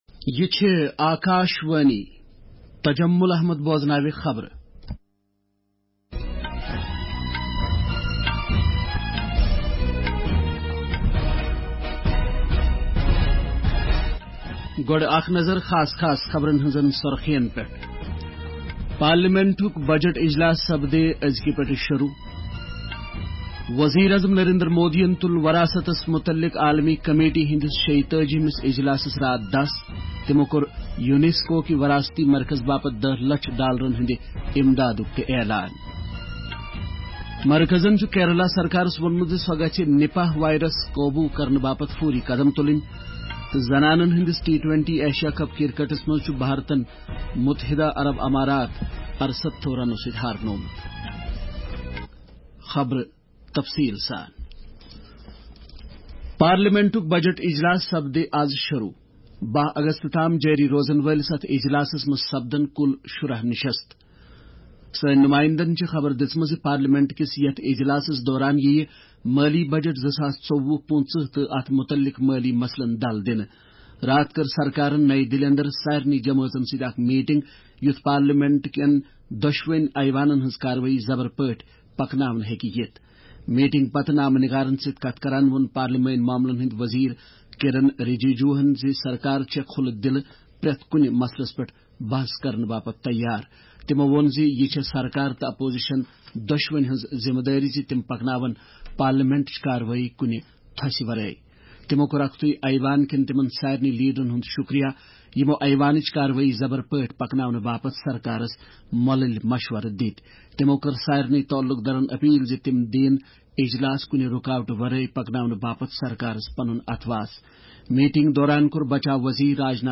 Transcript summary Play Audio Morning News